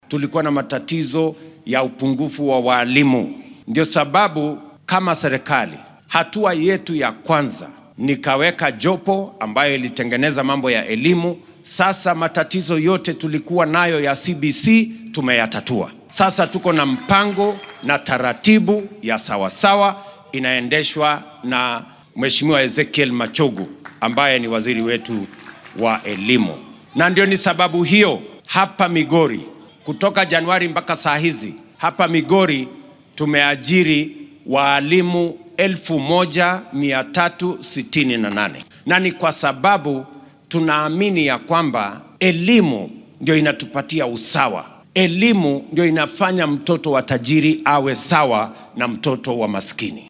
Madaxweynaha dalka William Ruto ayaa xilli uu ku sugnaa machadka farsamada ee deegaanka Uriri ee ismaamulka Migori sheegay in la xalliyay caqabadihii ku hor gudbanaa manhajka waxbarashada ee wadanka.
Ruto-4.mp3